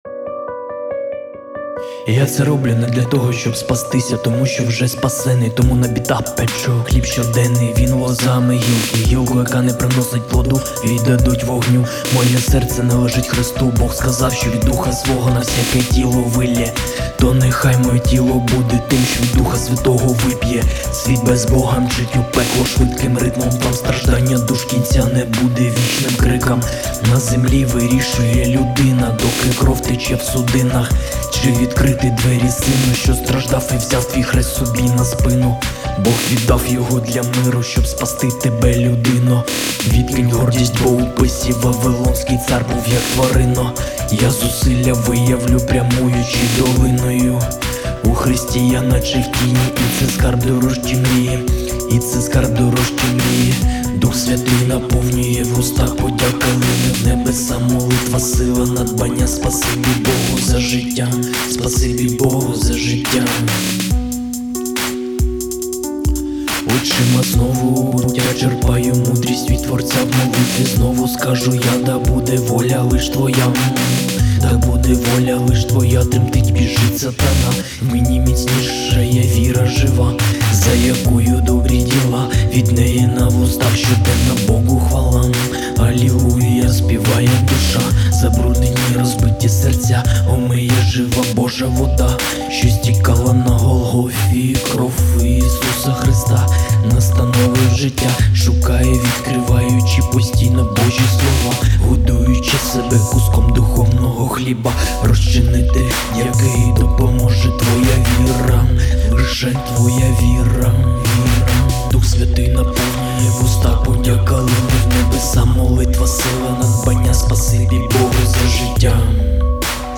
39 просмотров 63 прослушивания 1 скачиваний BPM: 142